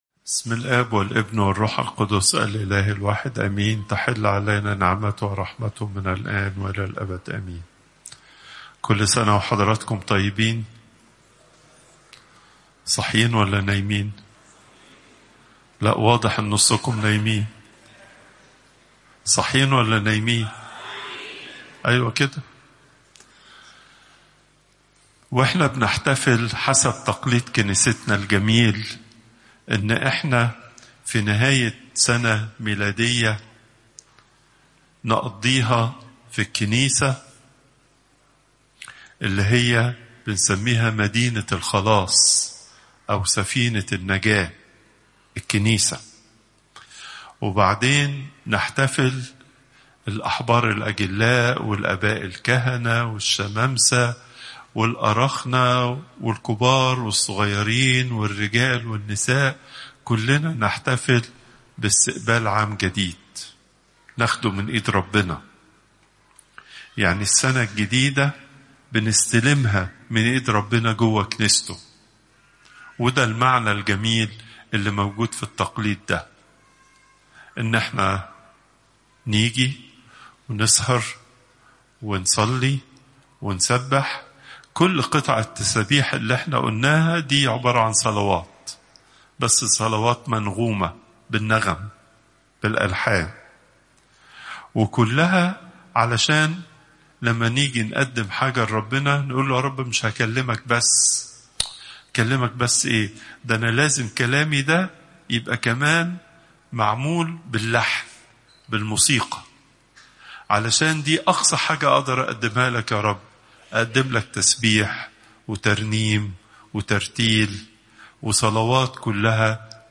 Popup Player تحميل الصوت البابا تواضروس الثانى الأربعاء، 31 ديسمبر 2025 27:26 المحاضرة الأسبوعية لقداسة البابا تواضروس الثاني الزيارات: 33